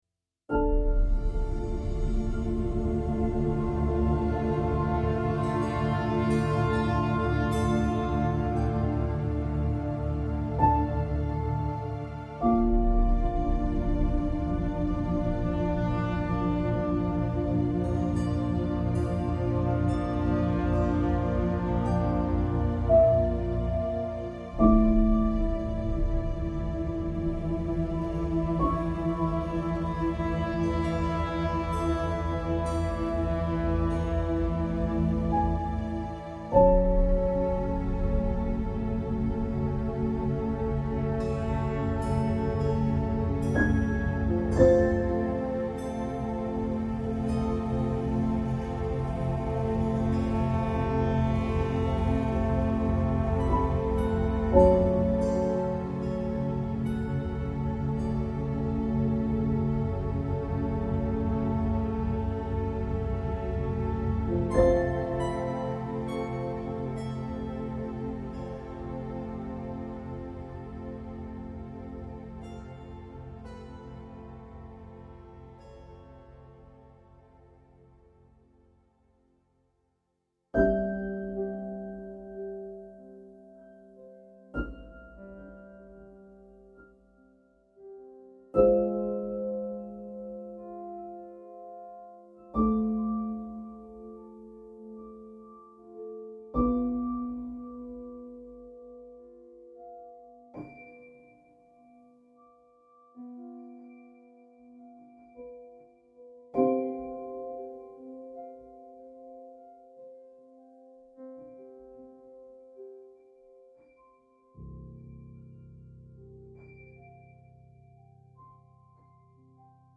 metal strings